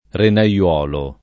vai all'elenco alfabetico delle voci ingrandisci il carattere 100% rimpicciolisci il carattere stampa invia tramite posta elettronica codividi su Facebook renaiolo [ rena L0 lo ] (oggi lett. renaiuolo [ rena LU0 lo ]) s. m.